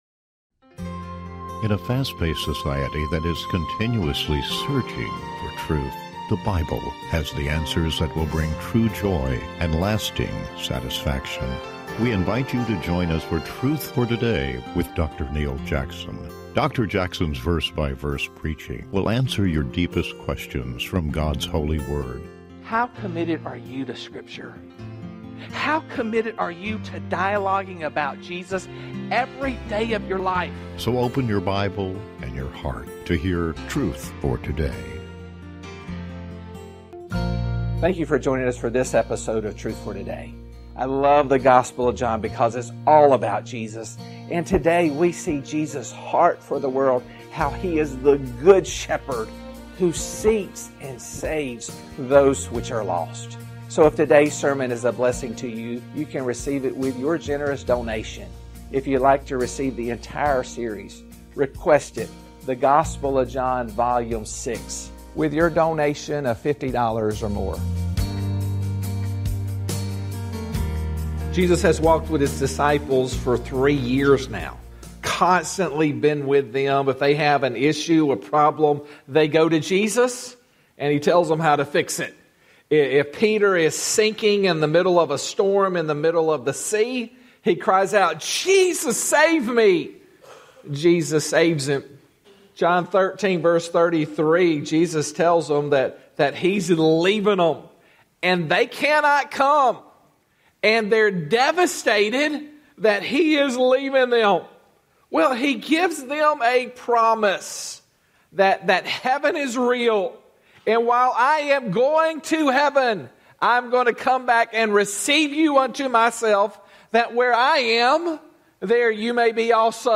Talk Show Episode, Audio Podcast, Truth For Today and The Sweet Holy Spirit on , show guests , about The Sweet Holy Spirit, categorized as Health & Lifestyle,Love & Relationships,Philosophy,Emotional Health and Freedom,Christianity,Inspirational,Motivational,Society and Culture